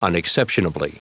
Prononciation du mot unexceptionably en anglais (fichier audio)
Prononciation du mot : unexceptionably